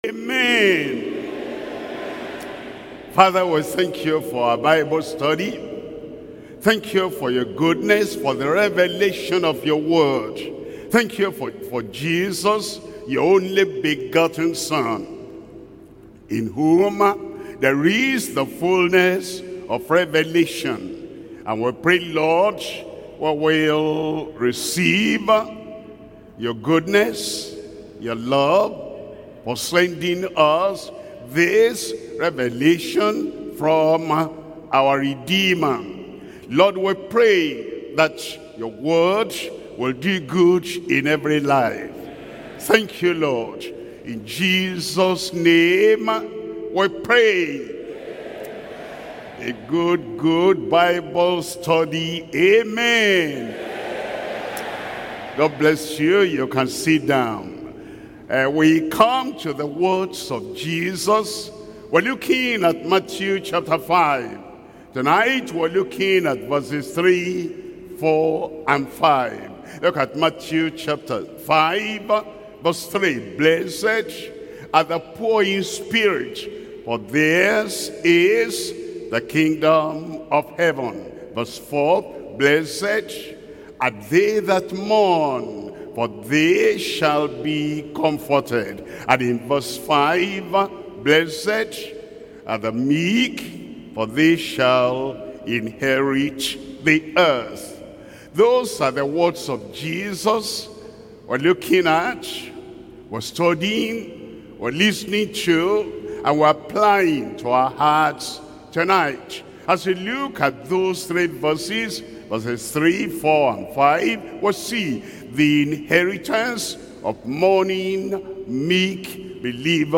Sermons – Deeper Life Bible Church Dubai, UAE
Bible Study